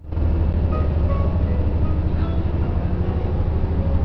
・KTR700・800形車内チャイム
シンプルながら車内チャイムが３曲用意されています。